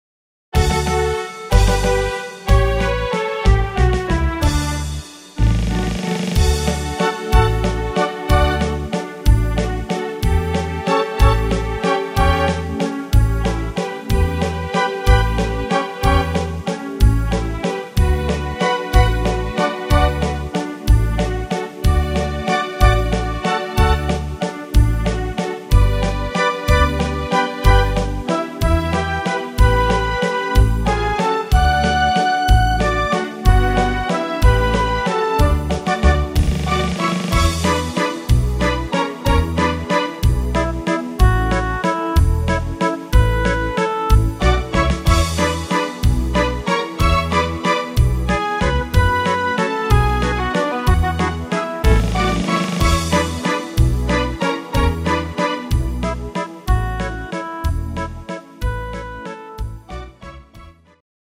instr. Strings